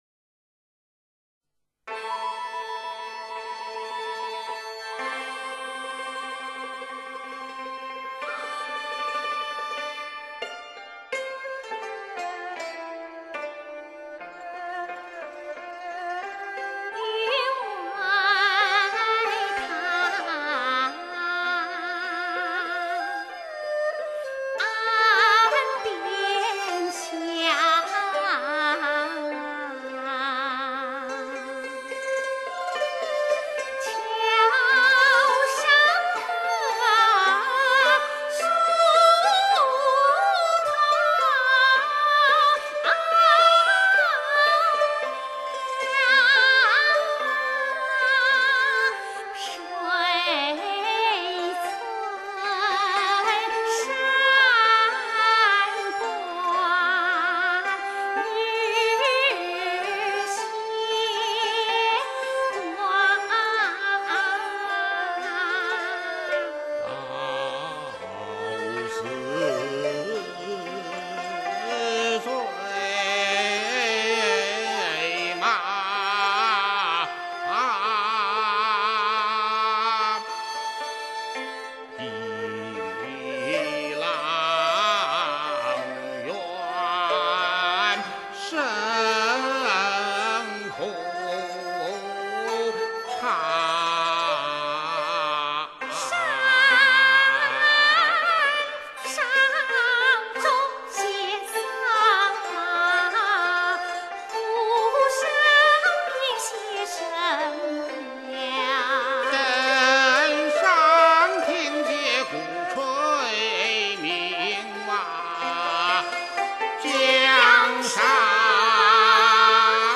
元散曲，人声演唱